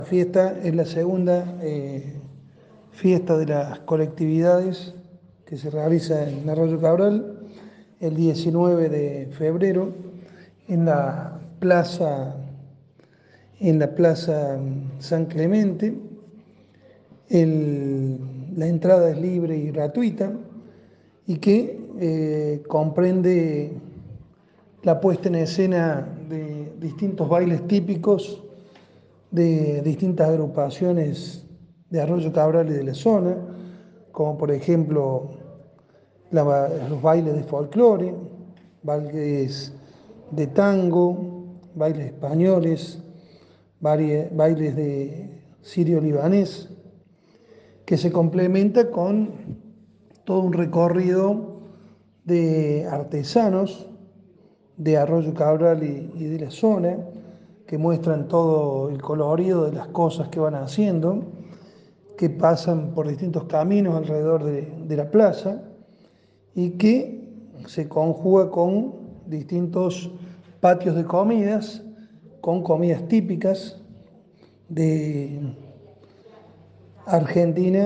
En comunicación con nuestro portal de noticias el intendente Pablo alcalino nos decía los siguiente: